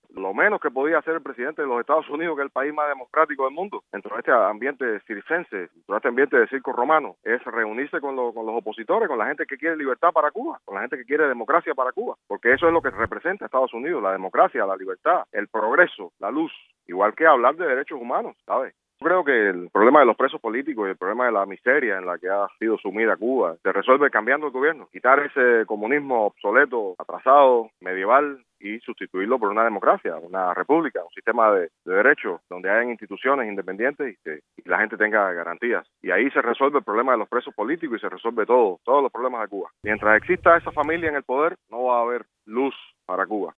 Periodista independiente